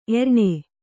♪ erṇe